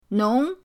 nong2.mp3